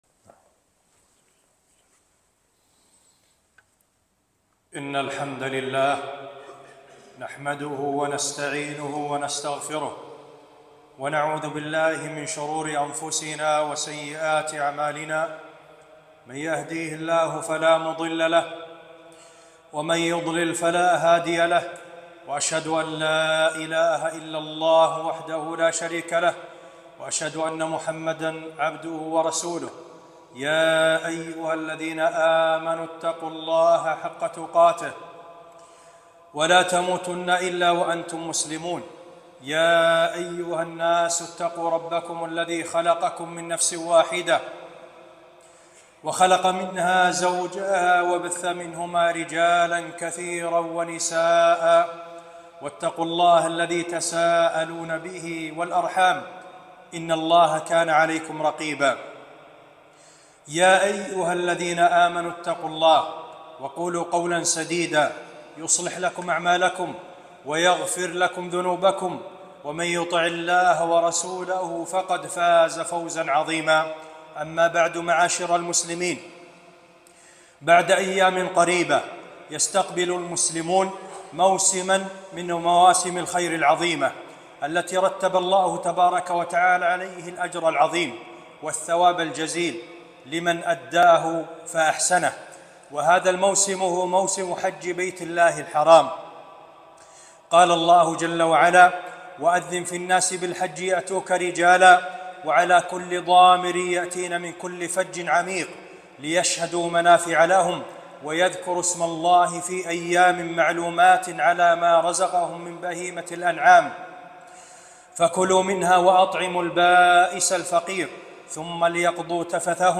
خطبة - فريضة الحج